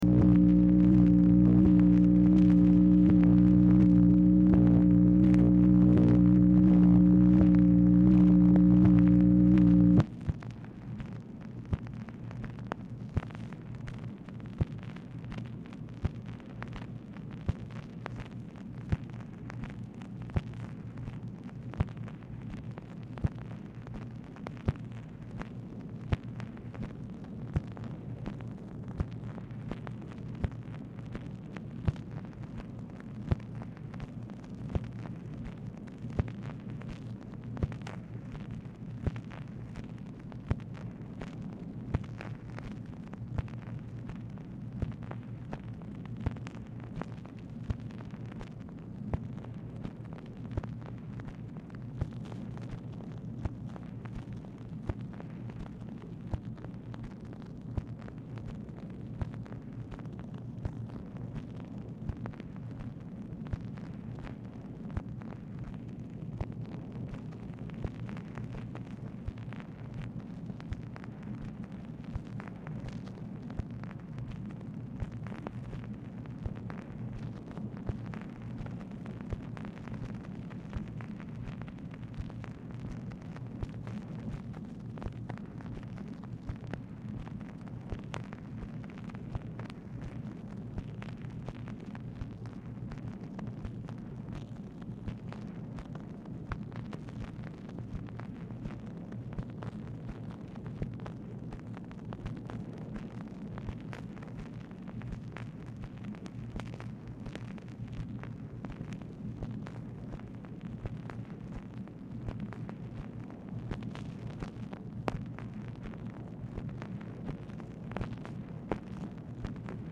Telephone conversation # 13568, sound recording, MACHINE NOISE, 10/22/1968, time unknown | Discover LBJ
Format Dictation belt
White House Telephone Recordings and Transcripts Speaker 2 MACHINE NOISE